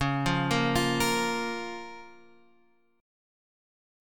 C#6 chord